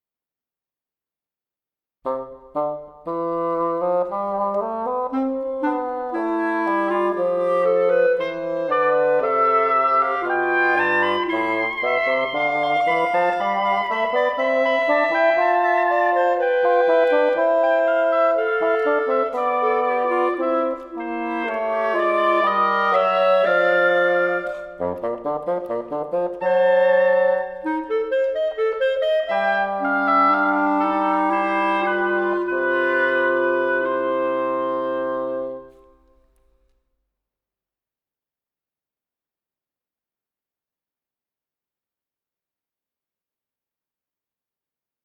スタジオ・ヴァージョン(木管合奏)